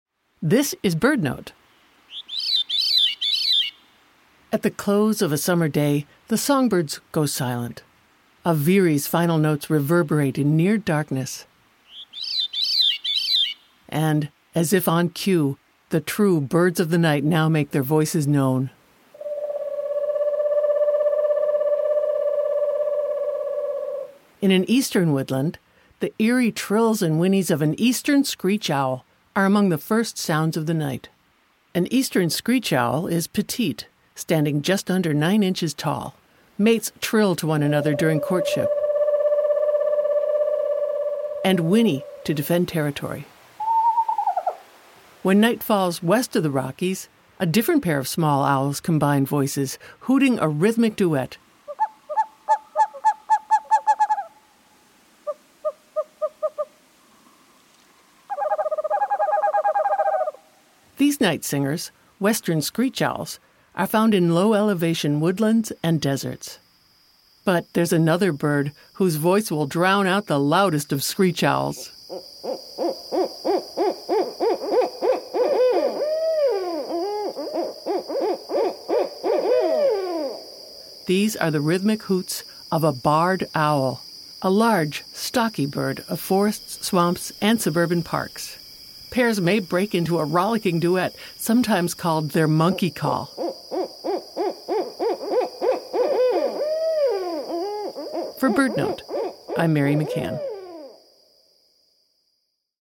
As if on cue, the birds of the night make their voices known. In an Eastern woodland, the eerie trills and whinnies of an Eastern Screech-Owl are among the first sounds of the night. Meanwhile, as night falls west of the Rockies, a Western Screech-Owl calls out. But there’s another bird whose voice will drown out the loudest of screech-owls: the Barred Owl! Pairs may break into a rollicking duet, sometimes called their “monkey call.”